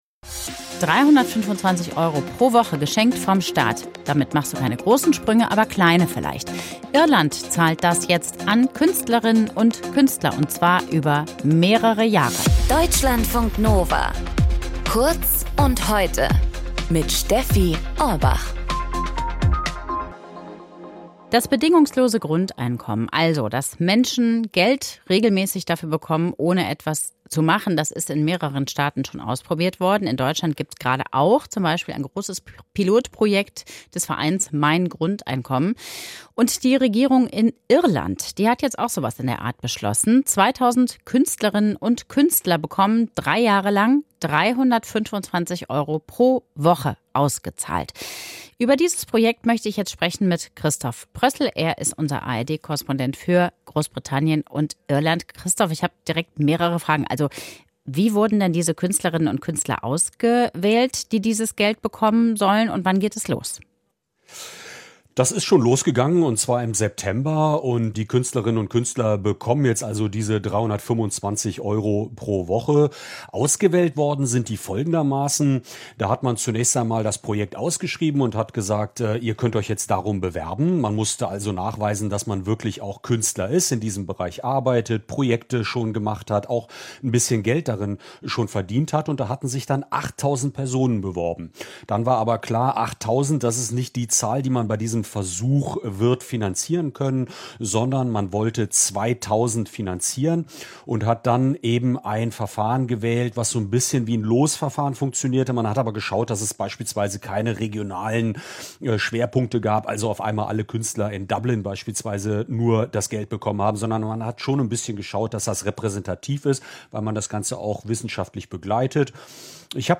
Feature Podcast abonnieren Podcast hören Podcast Zeitfragen-Feature Unsere Welt ist komplex, die Informationsflut überwältigend.